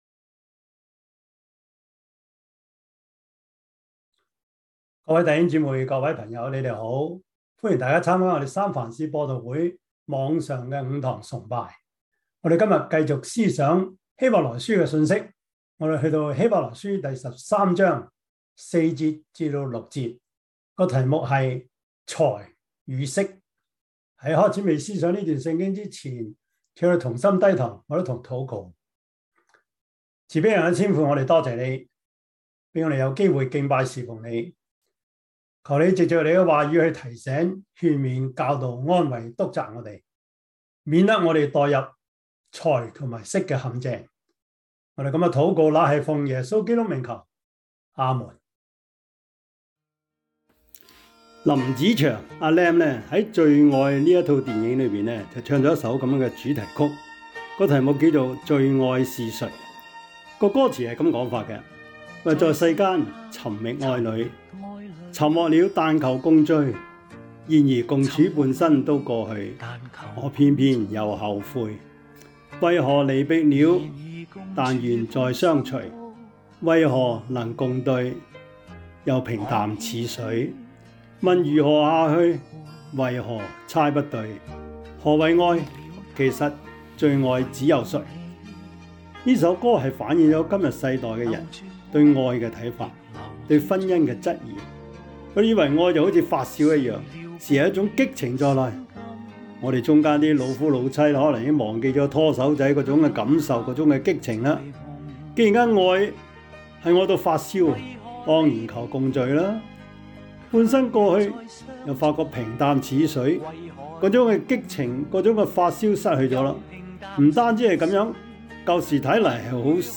希伯來書 13:4-6 Service Type: 主日崇拜 希 伯 來 書 13:4-6 Chinese Union Version